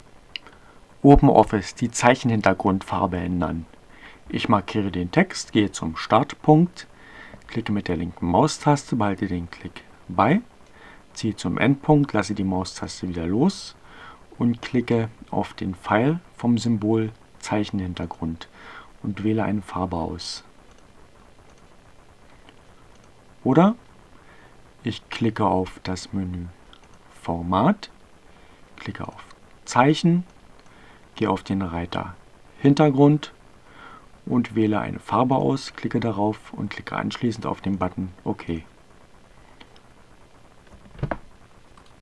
Tags: CC by-sa, Desktop, Fedora Core, Gnome, Linux, Neueinsteiger, Ogg Theora, ohne Musik, OpenOffice, screencast, short, Textverarbeitung